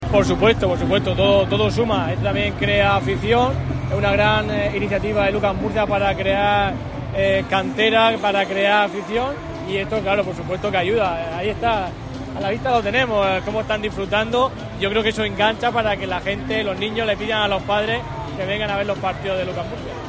Miguel Ángel Noguera, concejal de Deportes del Ayuntamiento de Murcia